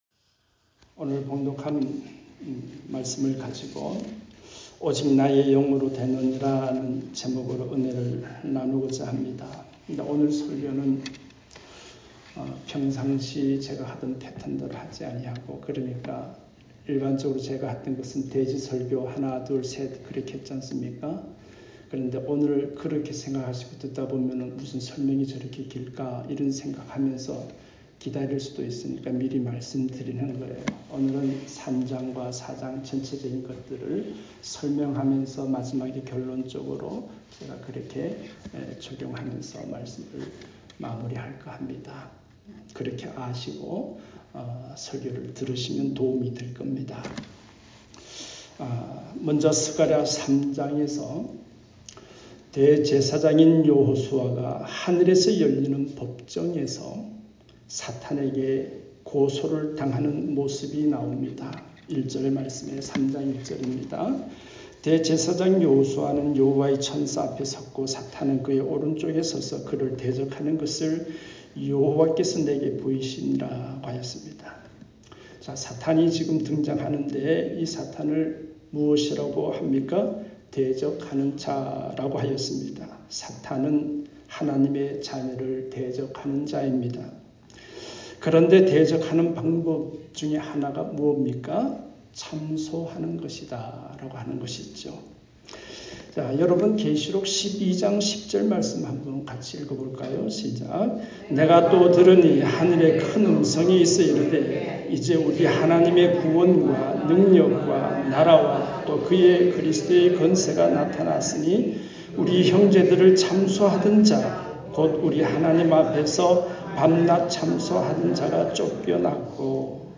Feb2sermon.mp3